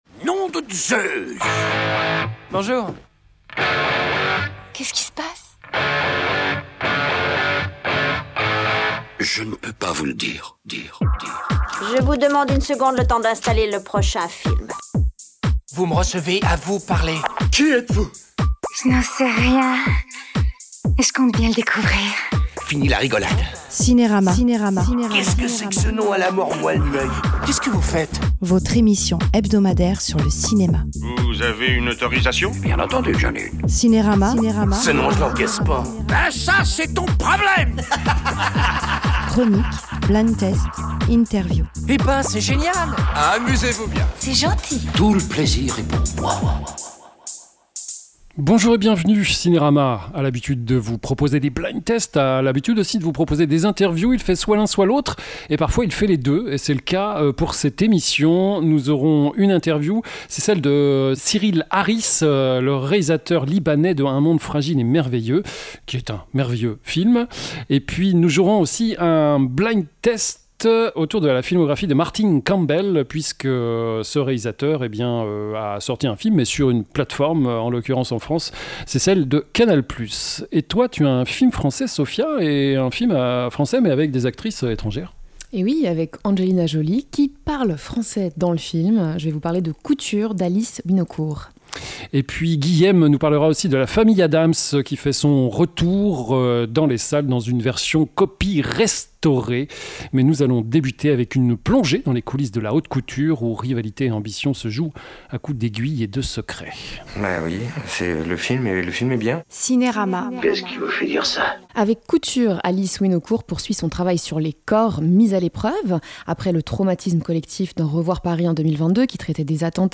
LES CHRONIQUES Coutures / Un monde fragile et merveilleux / La Famille Addams / Cleaner Ecoutez l’émission en podcast : CINERAMA Cinérama, une émission hebdomadaire sur le cinéma produite par Radio Divergence.